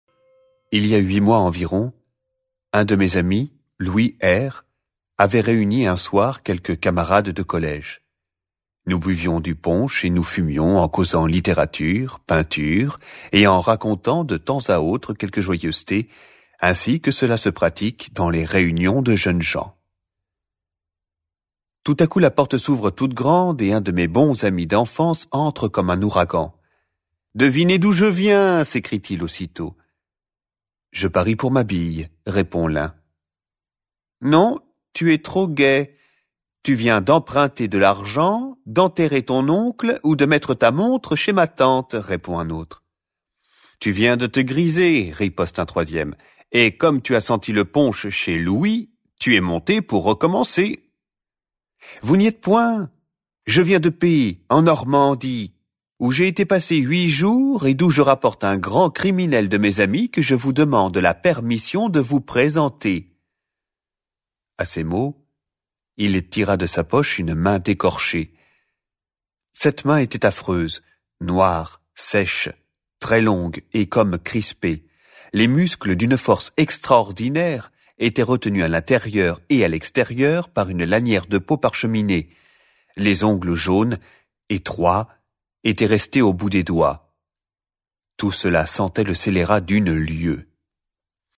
Click for an excerpt - La main d'écorché de Guy Maupassant de